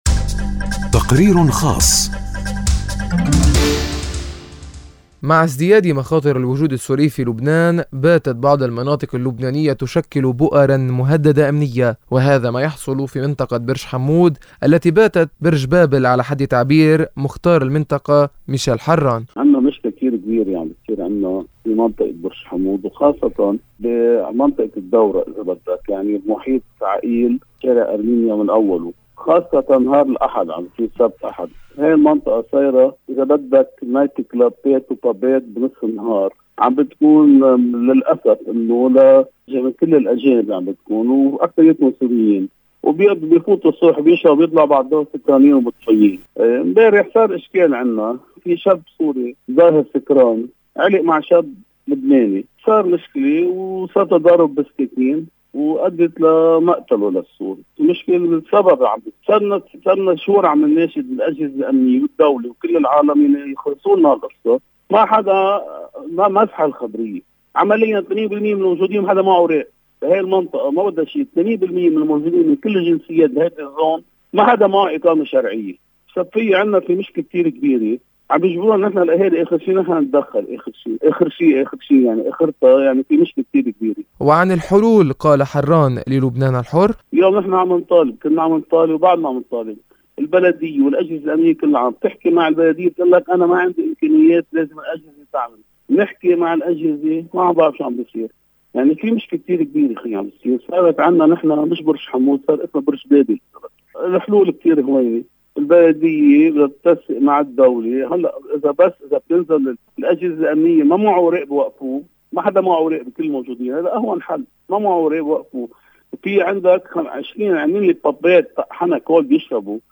التقرير